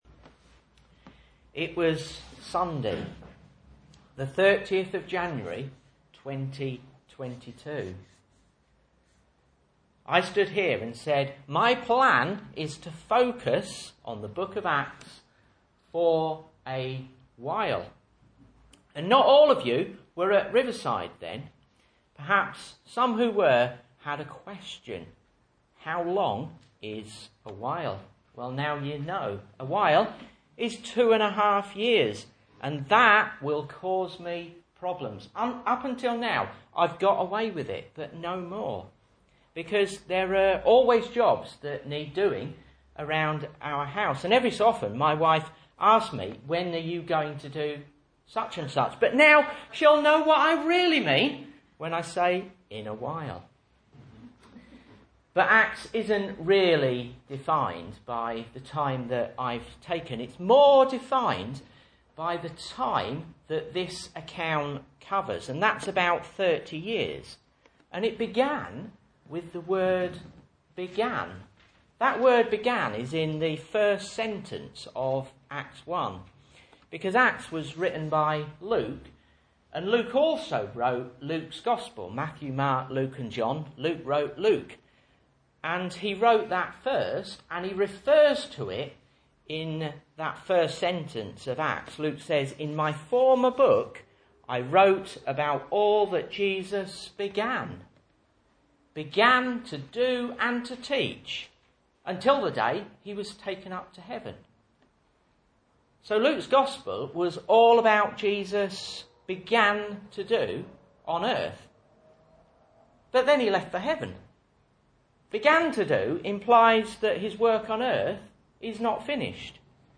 Message Scripture: Acts 28:16-31 | Listen